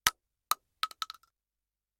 main Divergent / mods / Bullet Shell Sounds / gamedata / sounds / bullet_shells / shotgun_generic_6.ogg 27 KiB (Stored with Git LFS) Raw Permalink History
shotgun_generic_6.ogg